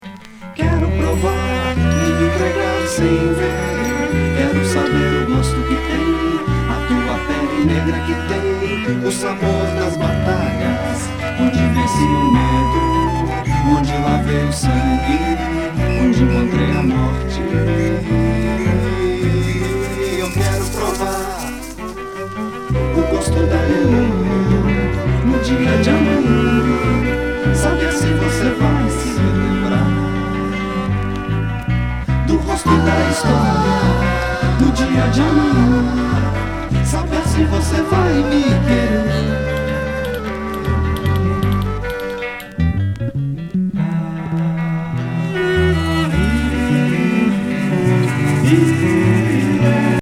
サイケ・フォーキー